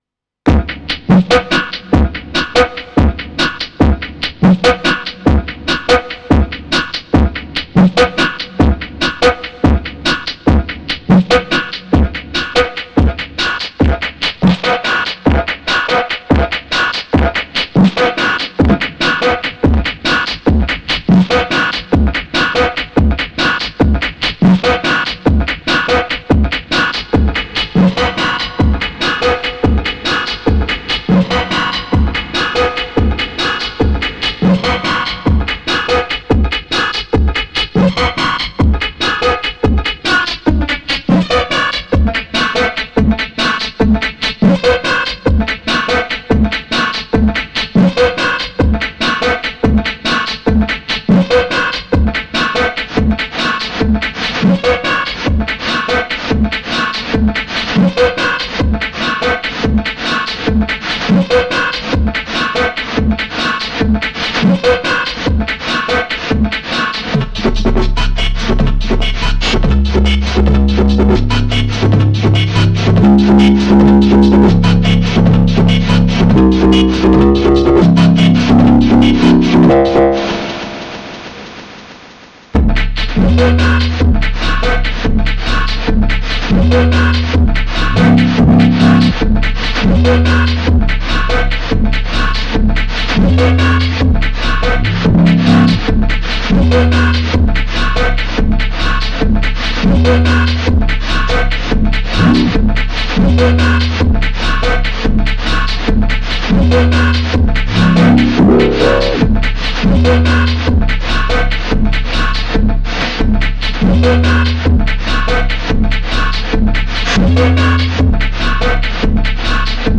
Running a drum loop into it and then running the 1v/oct out into an oscillator sounds amazing!!
Oscillators comes in at about 1 min